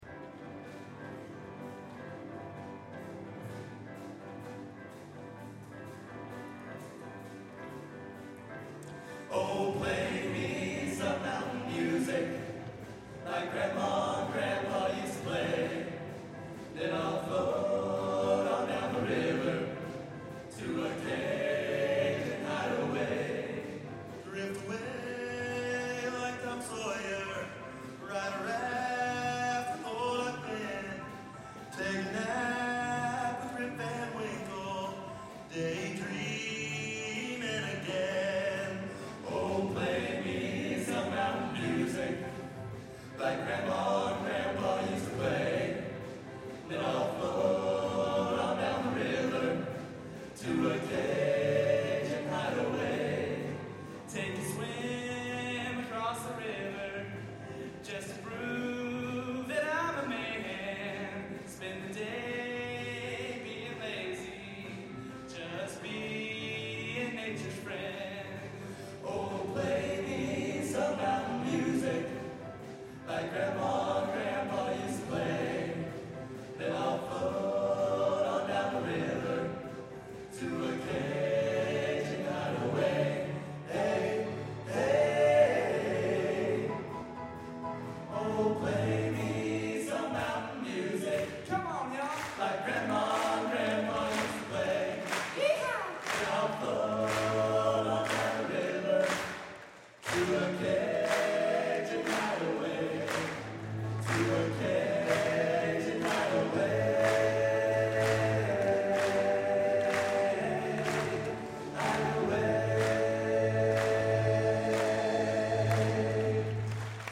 Collection: Bonita Springs, Florida Tour, 2008
Location: Bonita Springs, Florida
Genre: Country/Western | Type: